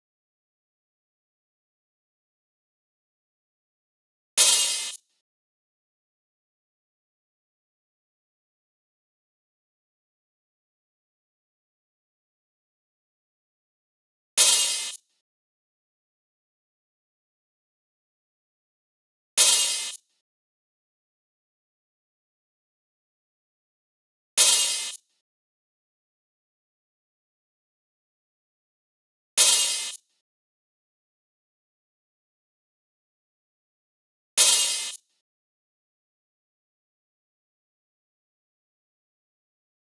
Sizzle 1.wav